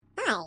Download Roblox Hi sound effect for free.